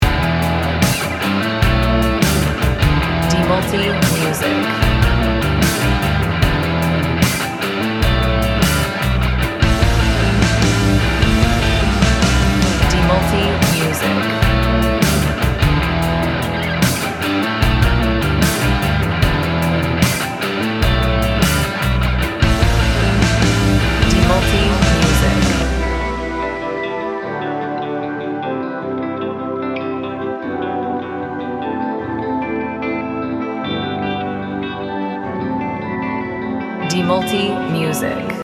Gym Music Instrumental